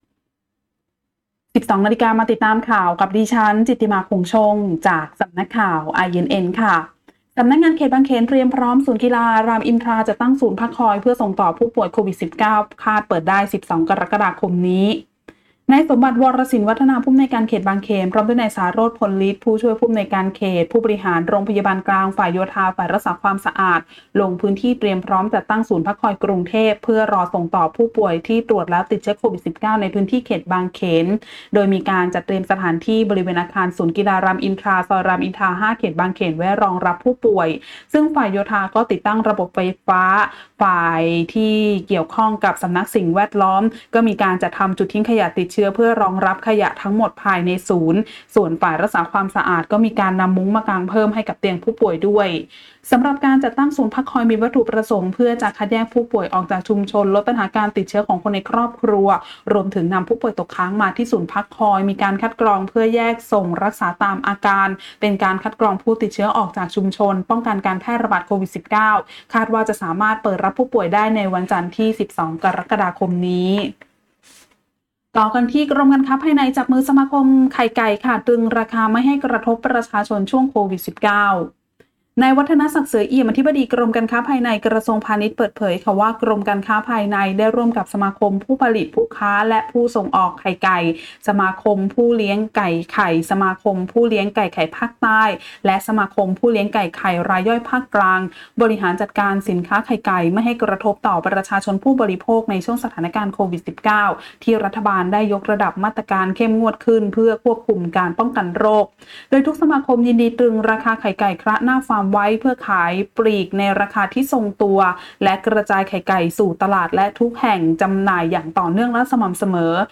ข่าวต้นชั่วโมง 12.00 น.